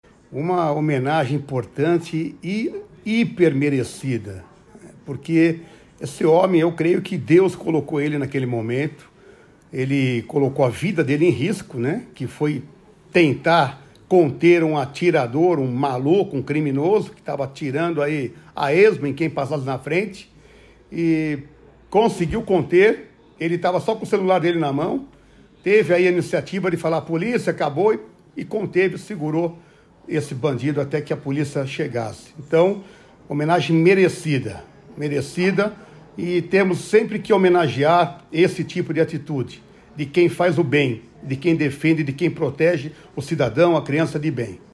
SONORA DEPUTADO RICARDO ARRUDA (PL)